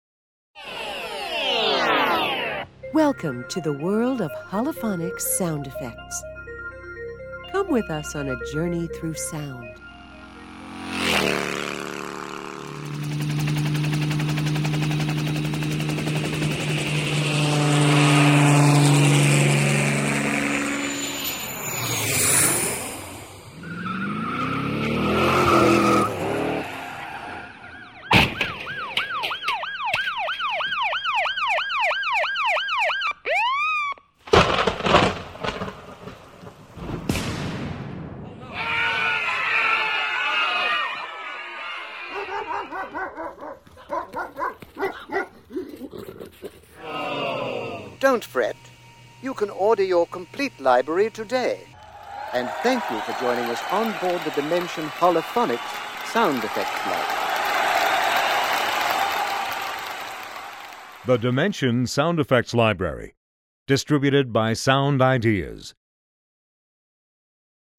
دانلود آهنگ هولوفونیک سه بعدی از افکت صوتی طبیعت و محیط
دانلود صدای هولوفونیک سه‌بُعدی از ساعد نیوز با لینک مستقیم و کیفیت بالا
جلوه های صوتی